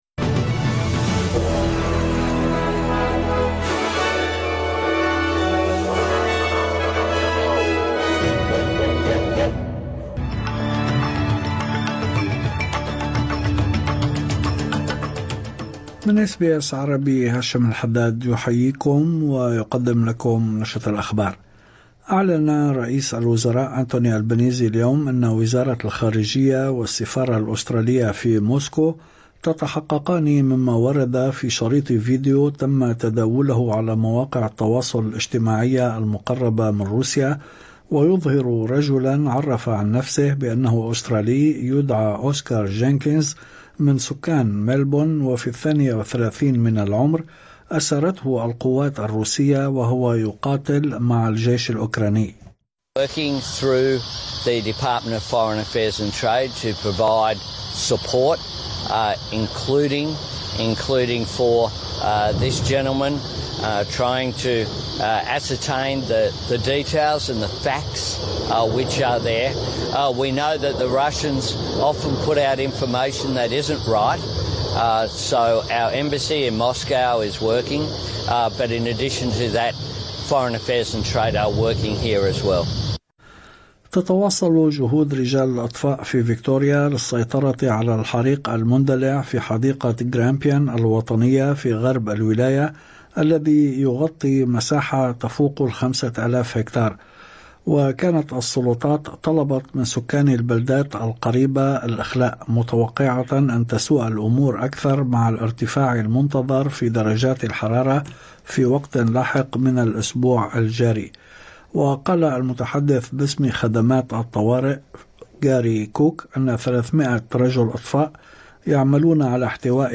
نشرة أخبار الظهيرة 23/12/2024